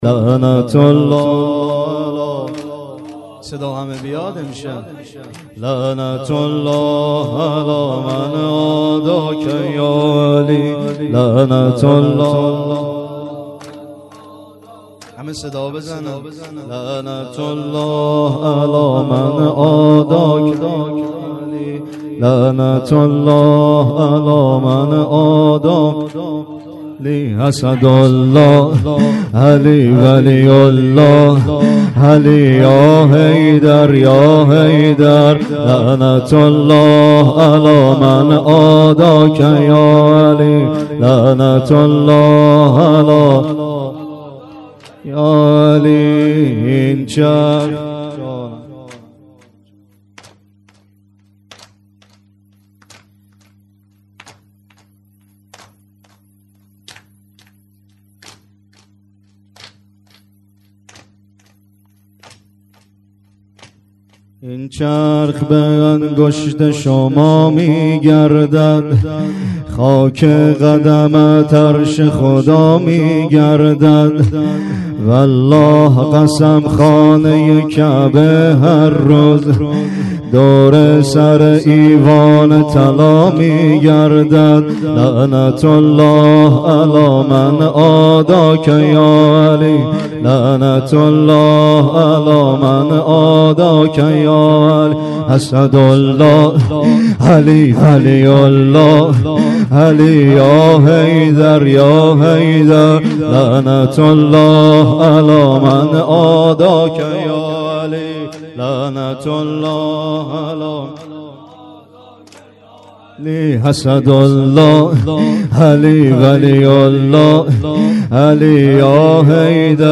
زمینه.لعنت-الله-علی-من-عاداک-یا-علی.mp3